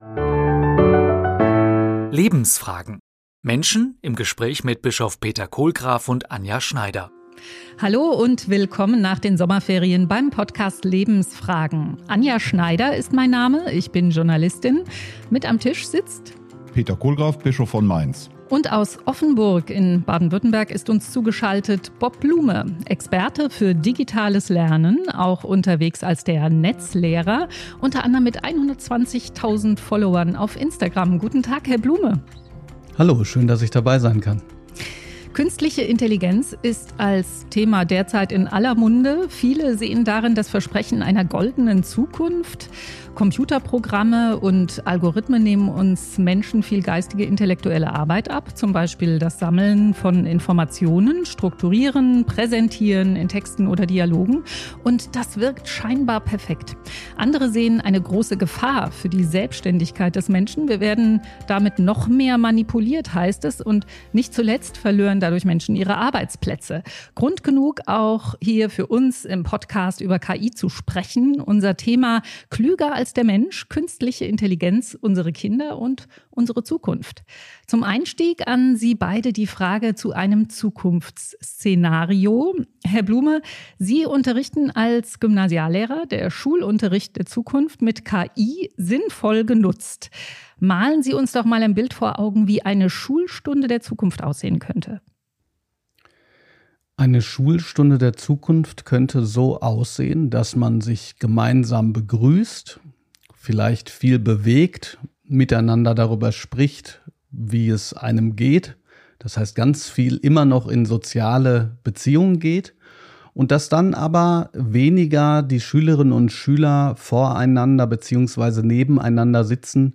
Lebensfragen - Menschen im Gespräch